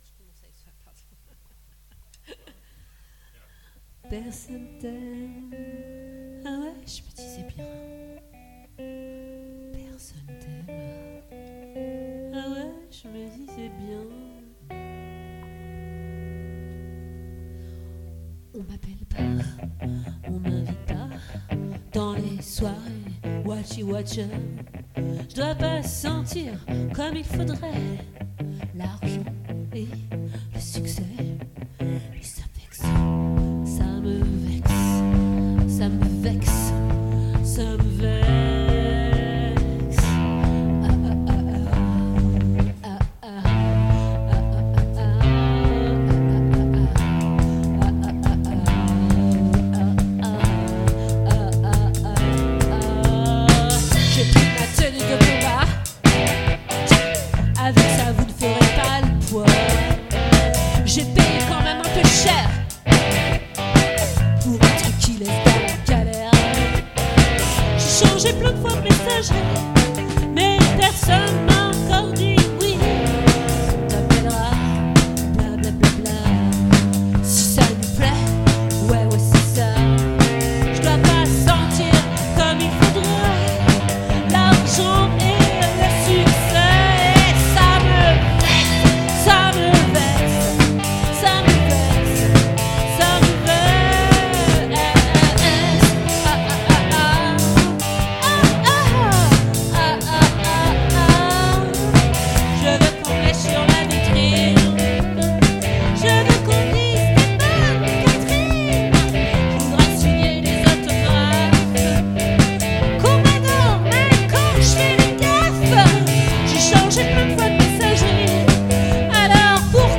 🏠 Accueil Repetitions Records_2023_03_29_OLVRE